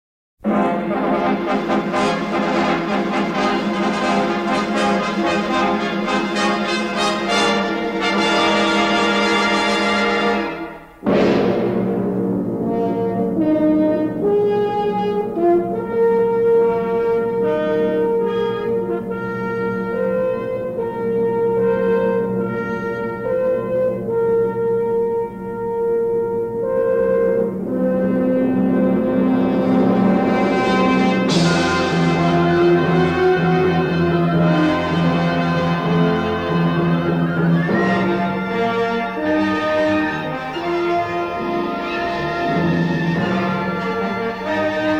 remastered from the original 1/4" stereo album tapes